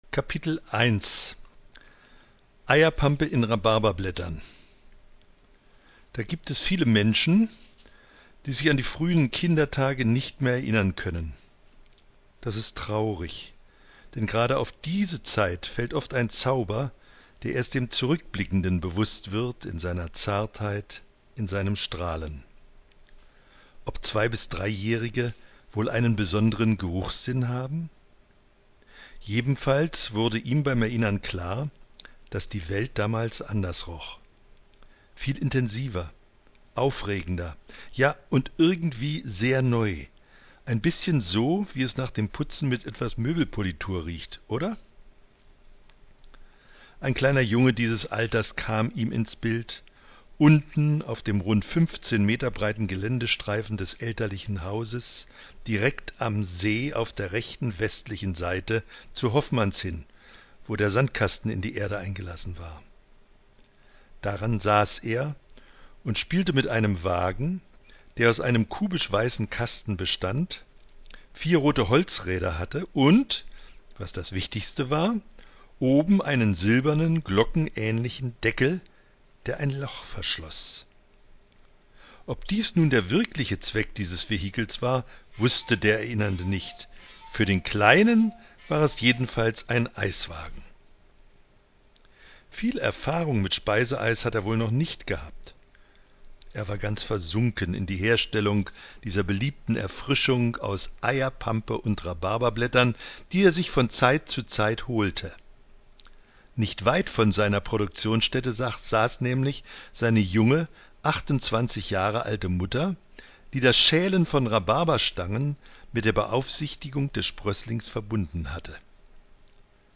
lesungkapitel1.mp3